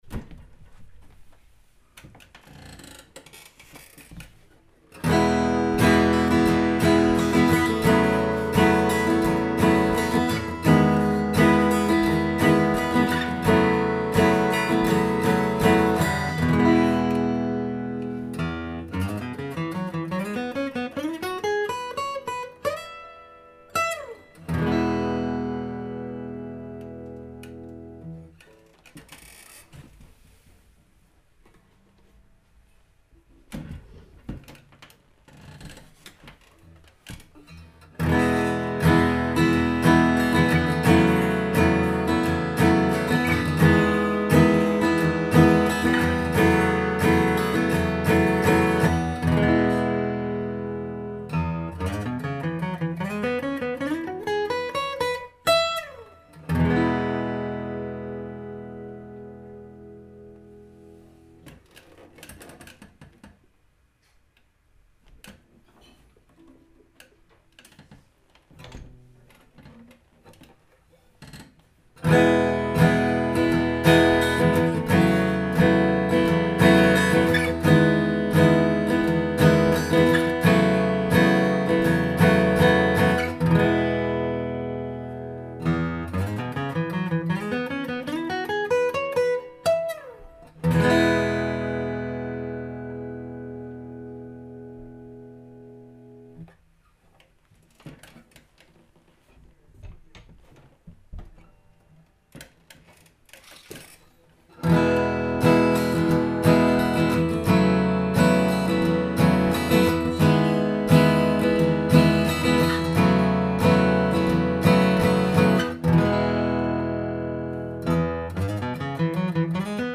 Я собираюсь позволить записям Zoom H4n говорить сами за себя.
Во второй записи E добавлен небольшой альпинистский риф.
Больше E Stuff на шести гитарах D28